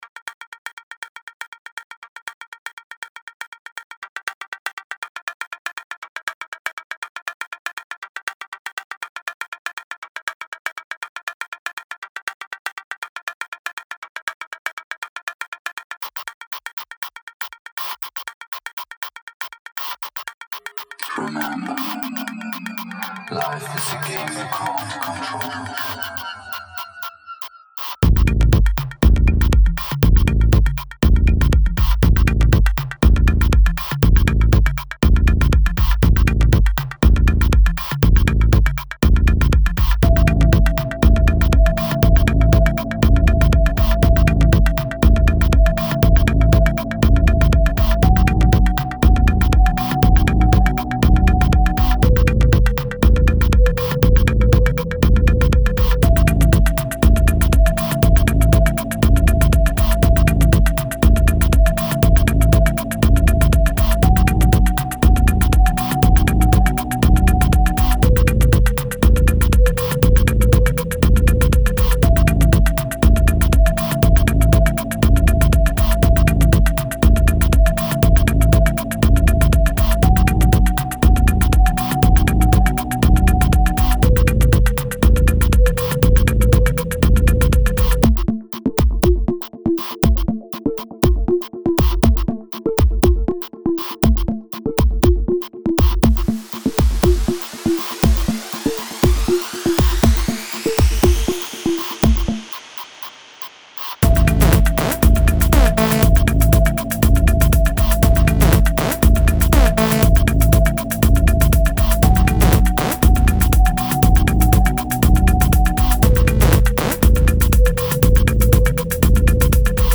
Genre Experimental